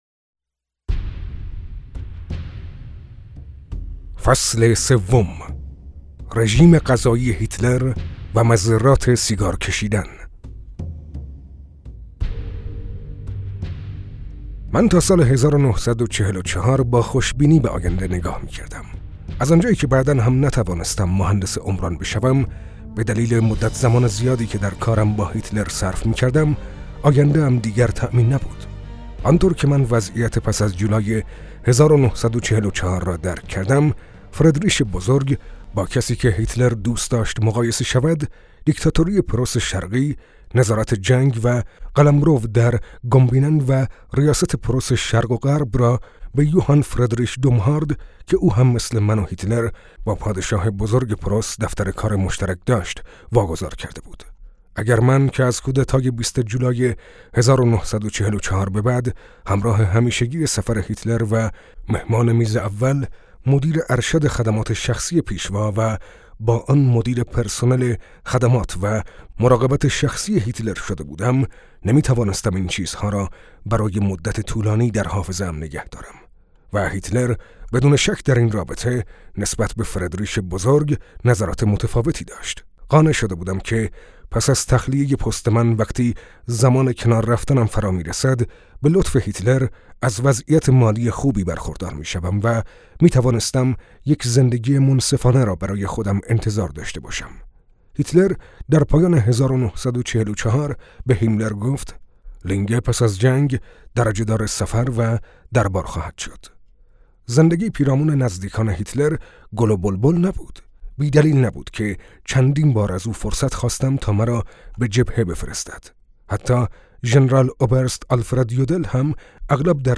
همچنین نمونه خوانش کتاب با پیشوا تا ابد؛ خاطرات شخصی پیشخدمت هیتلر جهت آشنایی بیشتر شما مخاطب عزیز در زیر آمده است :